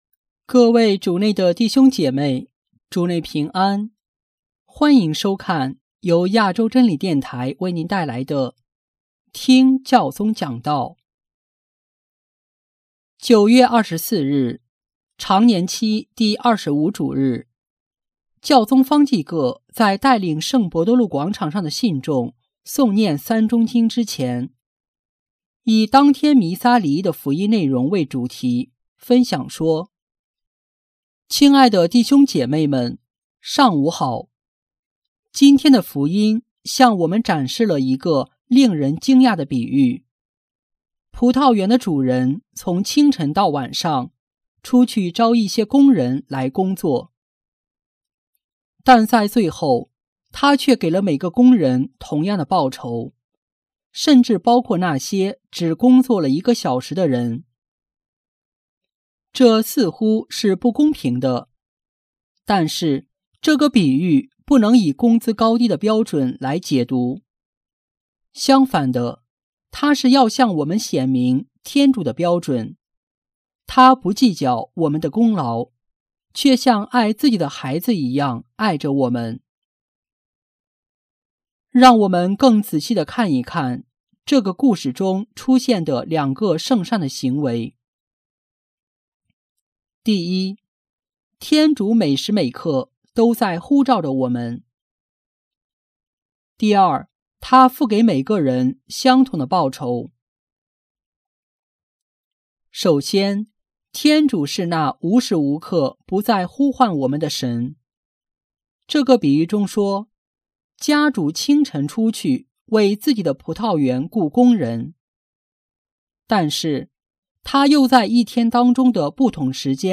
9月24日，常年期第二十五主日，教宗方济各在带领圣伯多禄广场上的信众诵念《三钟经》之前，以当天弥撒礼仪的福音内容为主题，分享说：